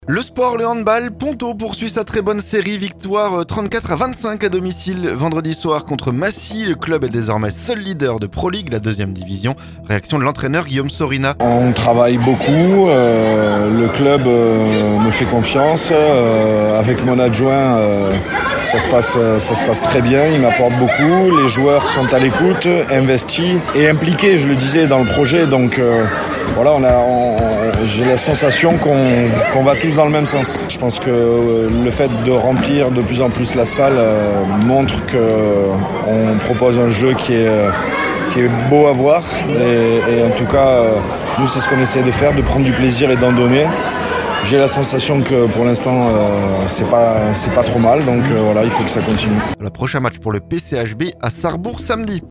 Victoire 34-25 à domicile vendredi soir contre Massy. Le club est désormais seul leader de Proligue, la deuxième division. Réaction pour Oxygène